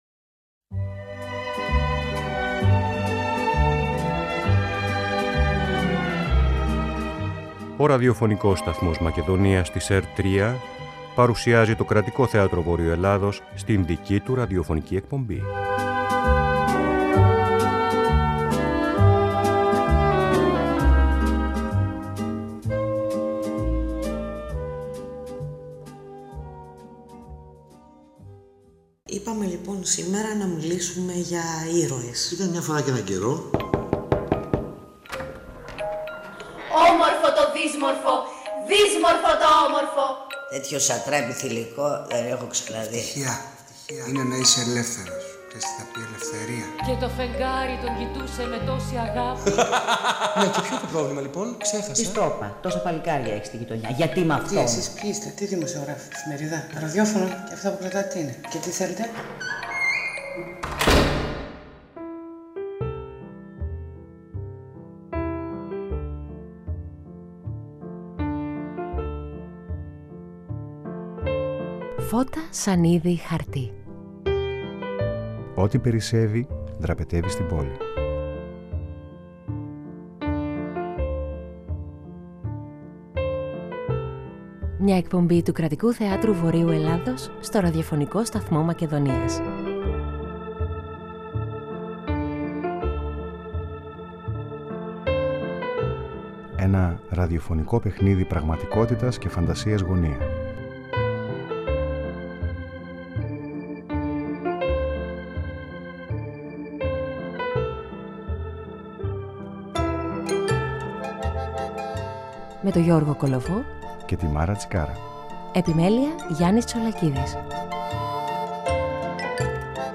24 συνειρμοί, ένα βασανιστικό θέμα συζήτησης και πολλή μουσική.